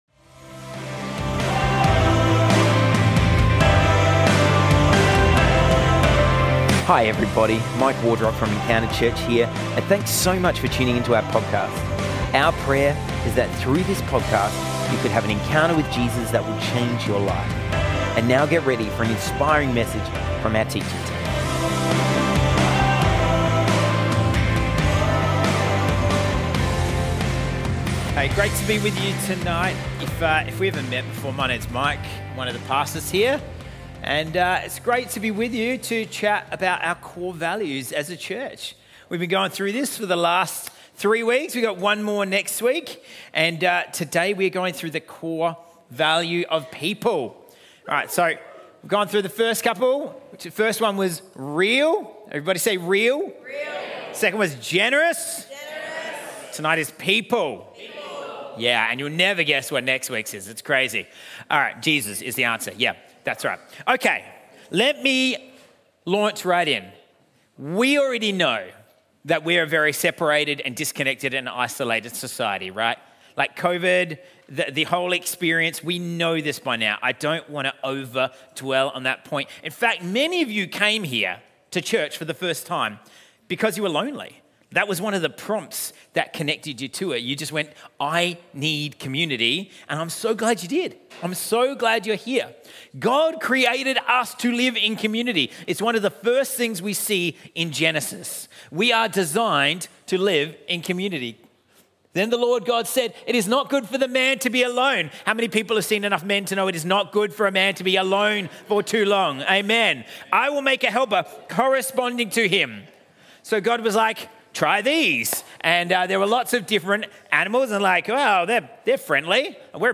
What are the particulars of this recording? Sunday Service from Encounter Church in Prospect, South Australia